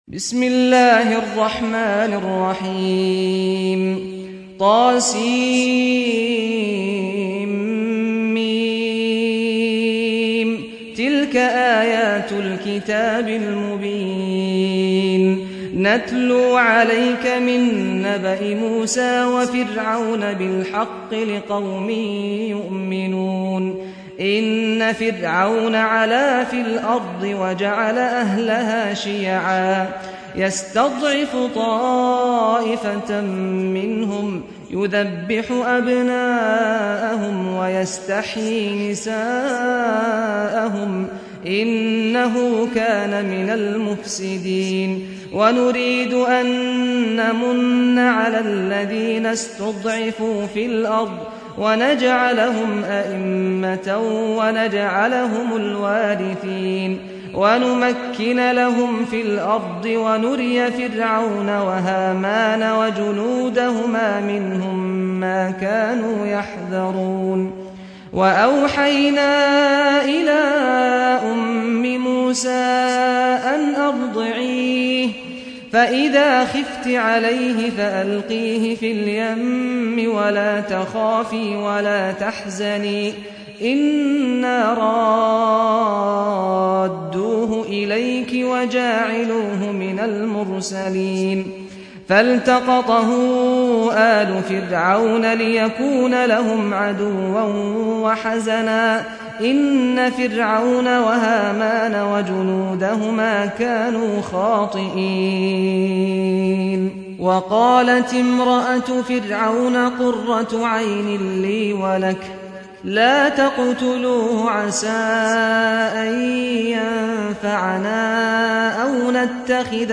سورة القصص | القارئ سعد الغامدي